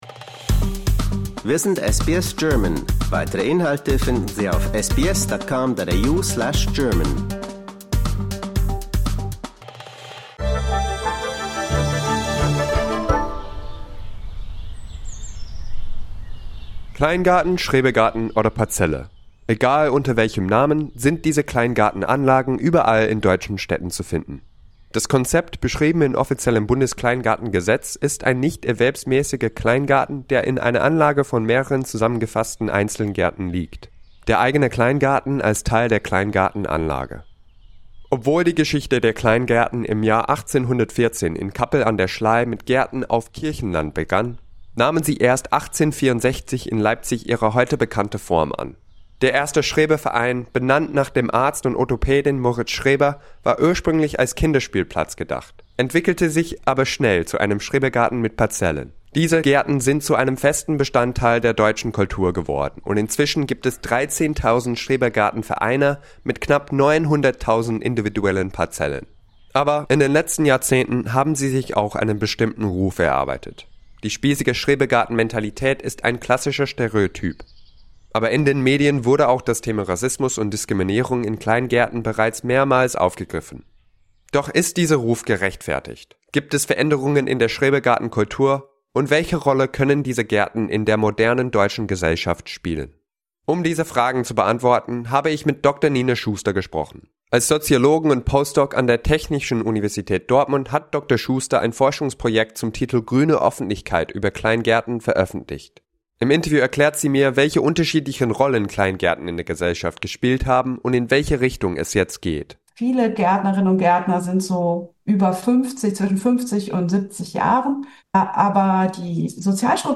Discover more stories, interviews, and news from SBS German in our podcast collection.